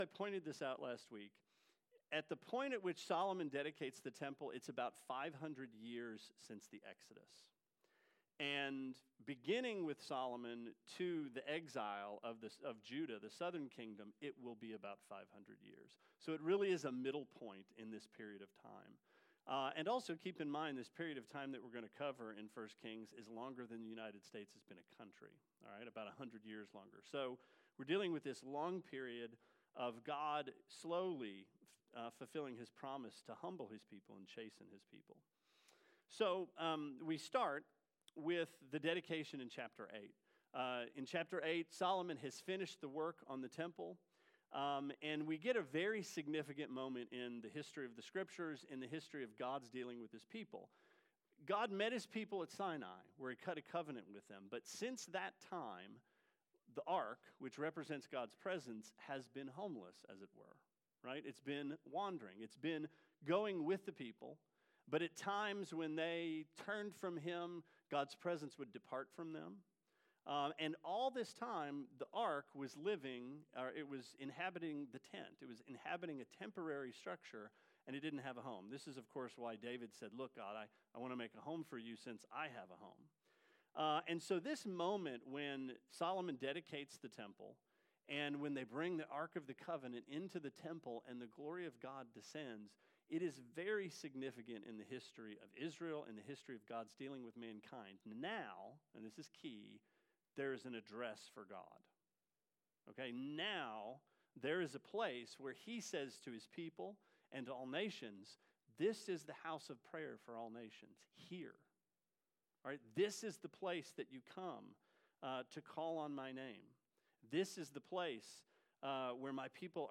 Sermon 07/27: 1 Kings 8-14
Sermon-7-27-18.mp3